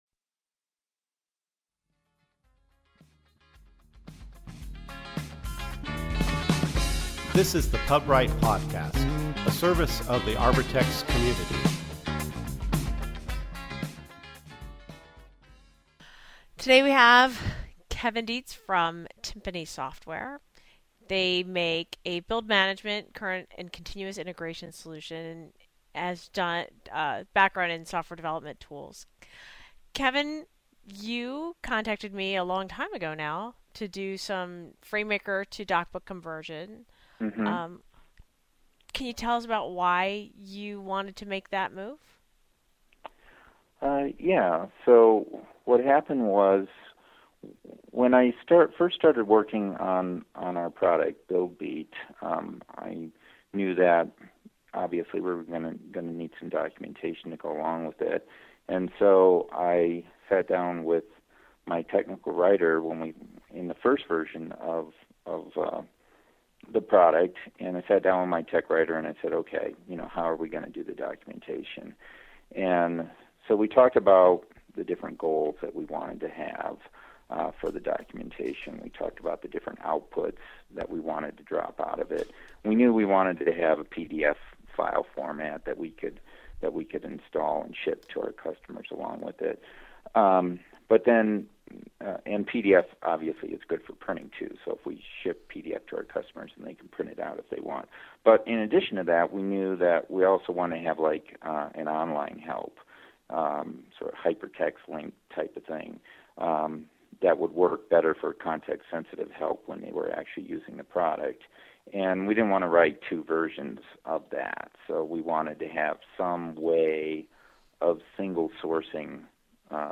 Podcast Posted: Interview